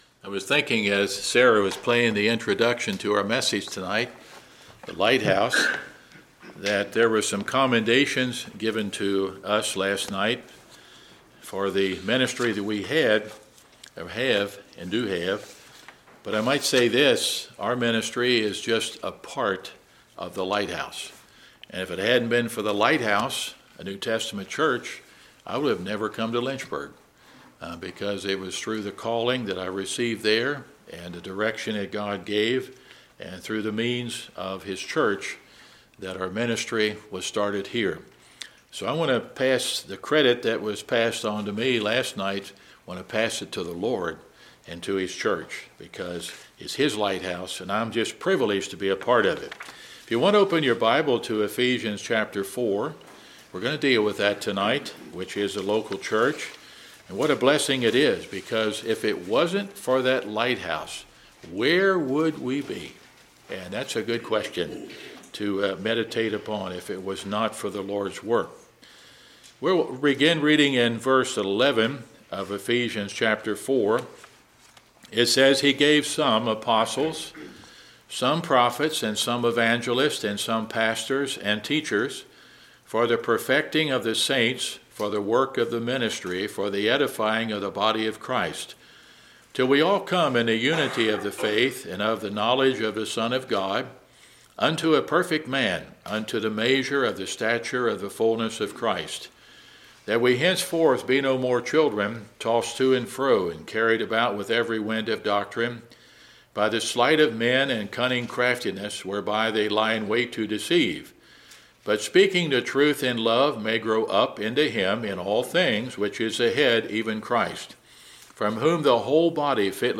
2025 Series: Sunday PM Sermon Book